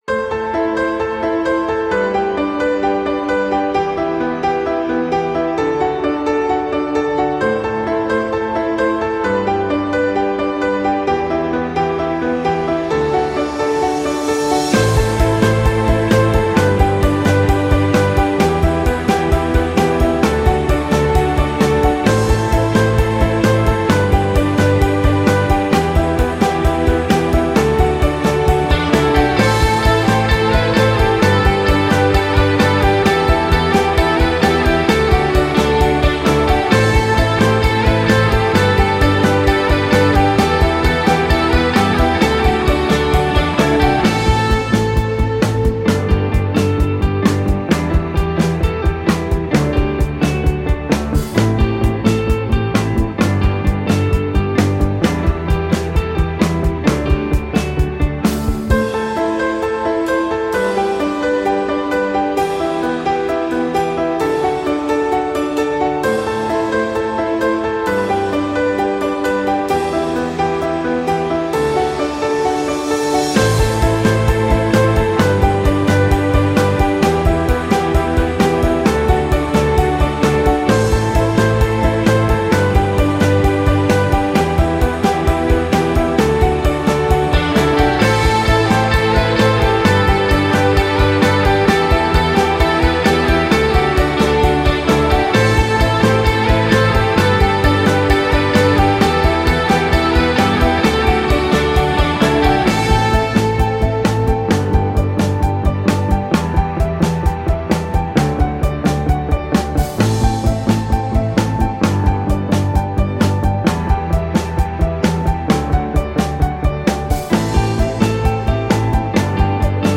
Красивая фоновая музыка без слов оптимистичная